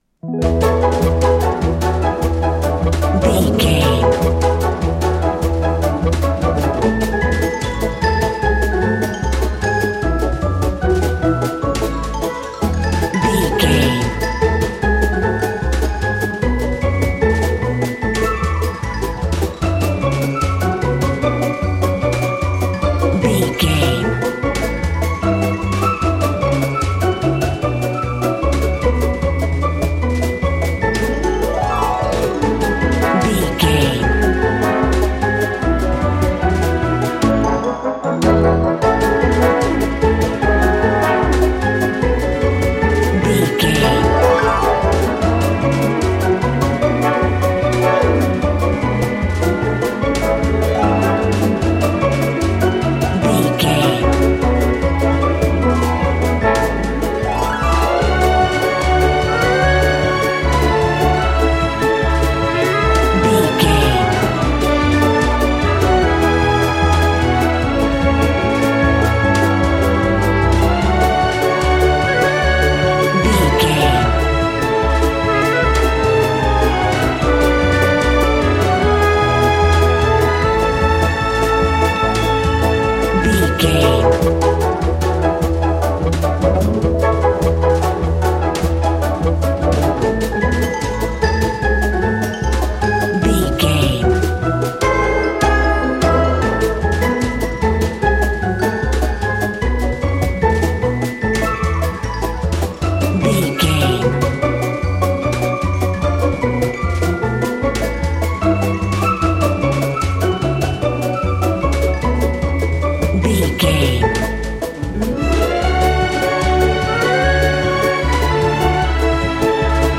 Ionian/Major
Fast
cheerful/happy
frantic
orchestra
strings